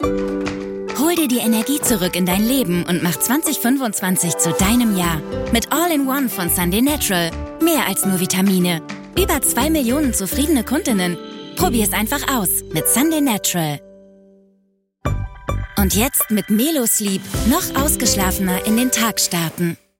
sehr variabel
Jung (18-30)
Rheinisch, Schwäbisch
Commercial (Werbung)